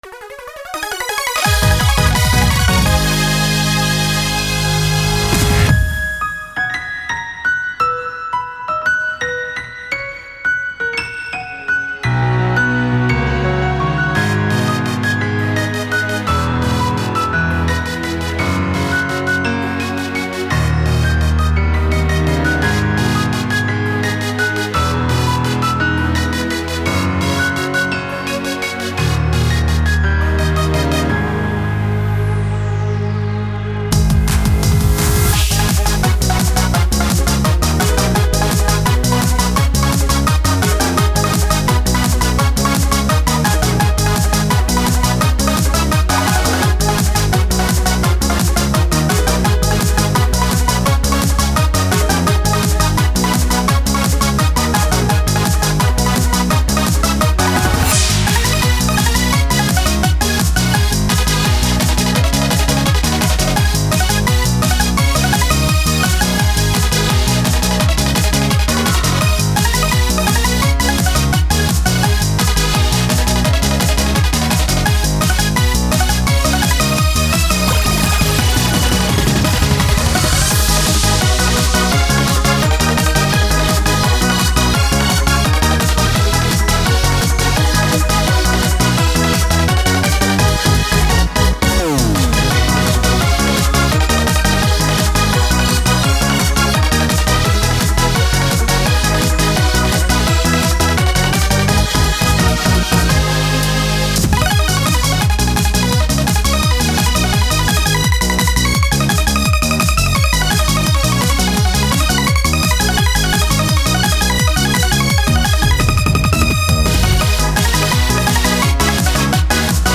ELECTRONIC EUROBEAT